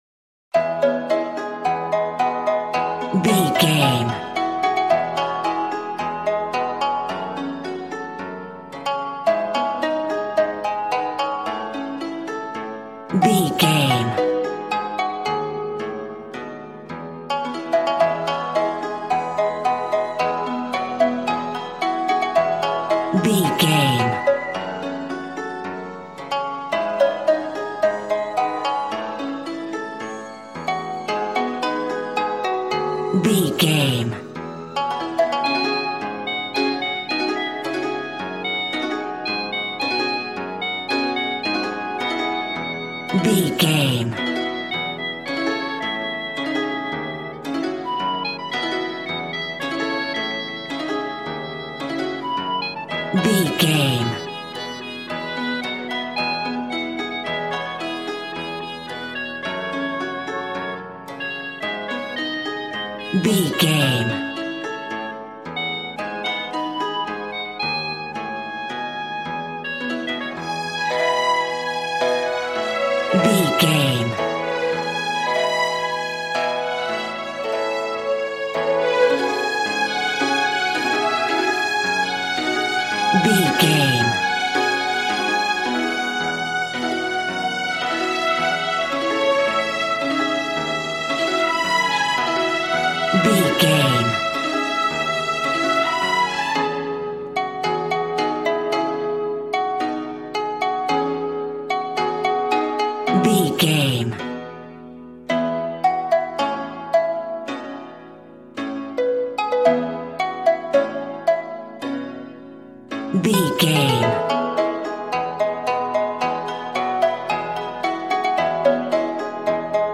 Aeolian/Minor
A♭
happy
bouncy
conga